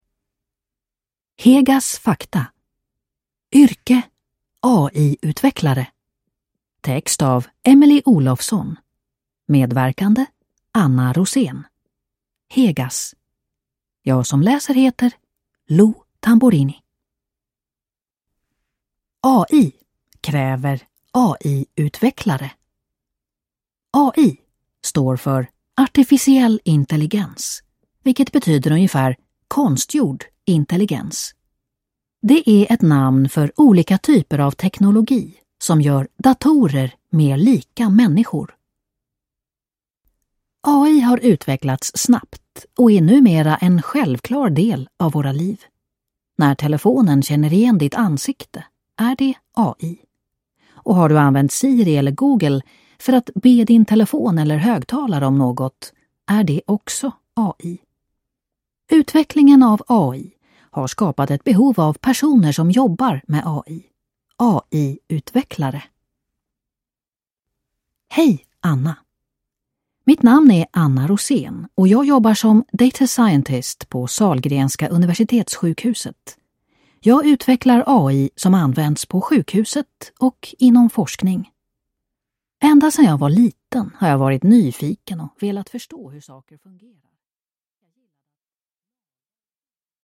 AI-utvecklare (ljudbok) av Red.